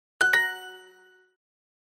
Download Duolingo Answer Correct Sound sound effect for free.
Duolingo Answer Correct Sound